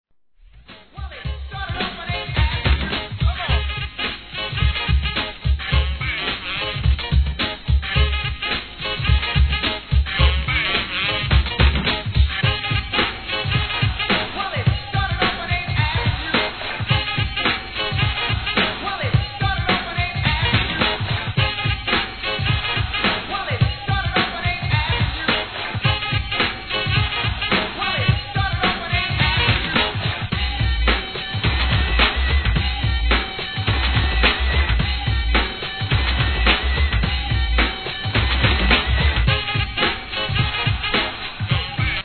1. HIP HOP/R&B
'90sのいけてるPARTYトラック物!!!